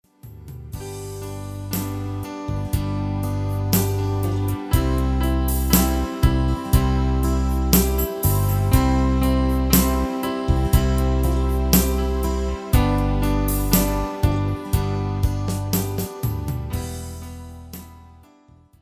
slovenské koledy v ľahkej úprave pre klavír